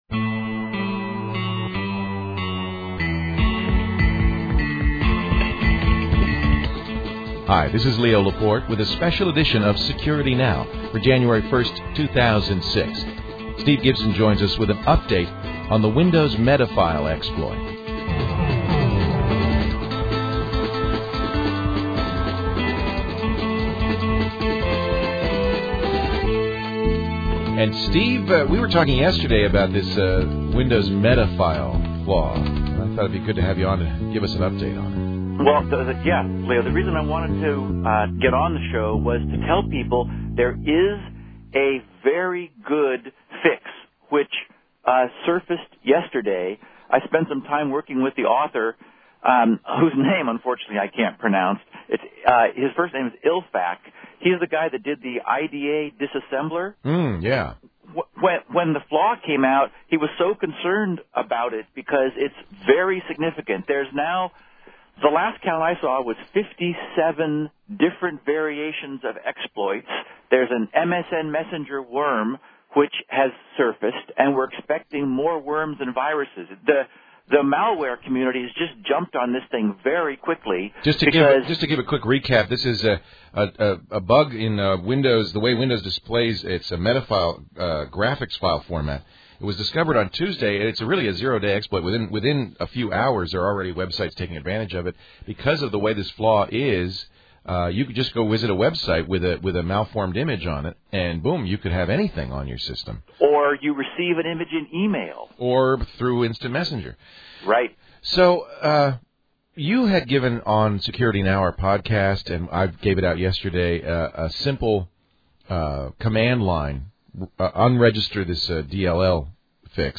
Since this was by telephone the audio quality is not great, but the high-quality and lower-quality MP3 audio files are available here: Higher-quality (larger) KFI Radio program update (64 kbps, MP3, 5.4 MB) Lower-quality (smaller) KFI Radio program update .